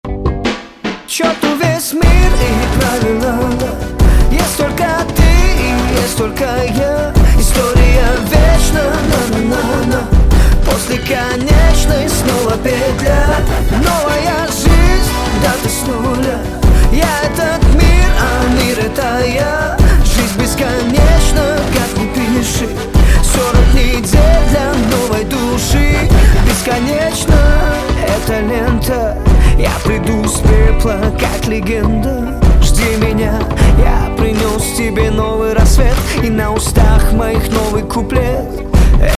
• Качество: 128, Stereo
поп
remix
Хип-хоп